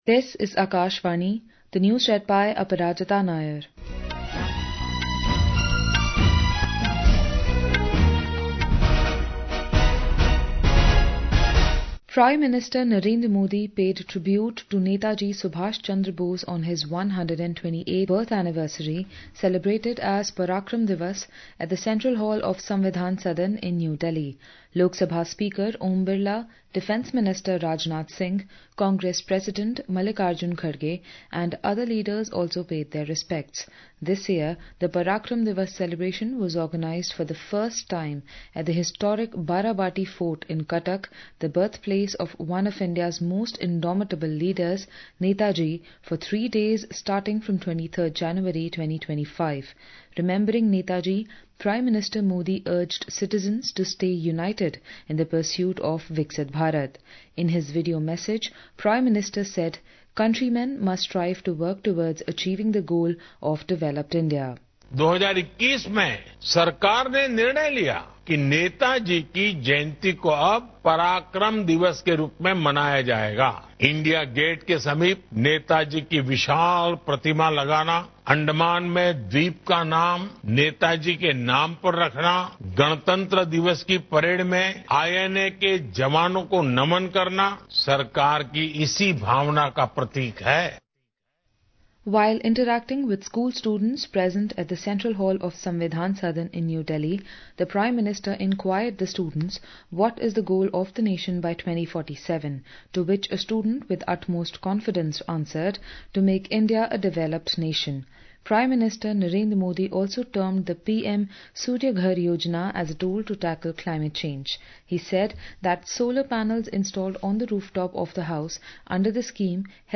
قومی بلیٹنز
Hourly News